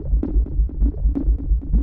Index of /musicradar/rhythmic-inspiration-samples/130bpm
RI_ArpegiFex_130-03.wav